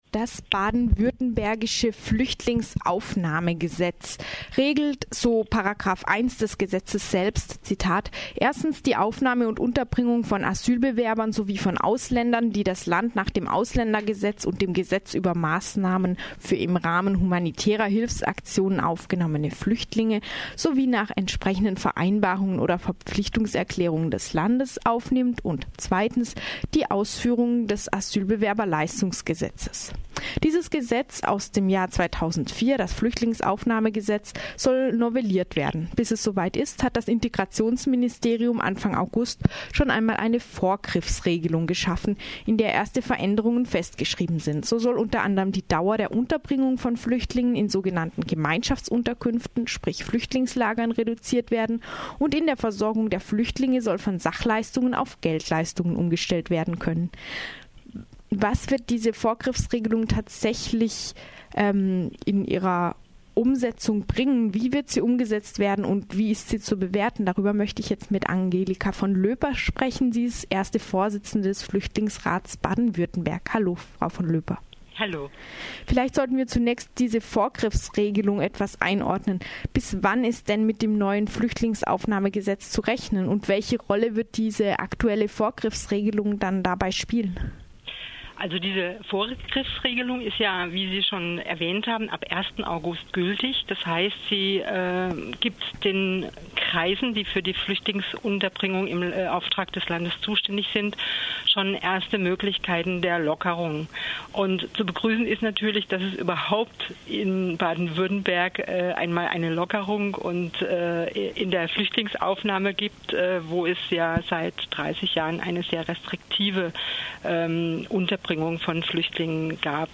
Inwieweit wird sie tatsächliche Verbesserungen bewirken? Ein Gespräch